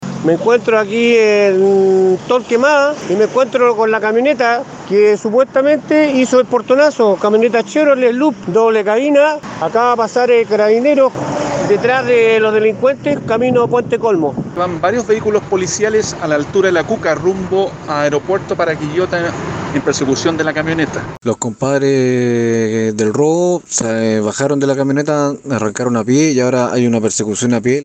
Luego de esto, otros auditores relataron el procedimiento policial que se origino a raíz de este portonazo.
“Acaba de pasar Carabineros detrás de los delincuentes”, indicaron mientras presenciaban el procedimiento.
cu-persecucion-mix-auditores-.mp3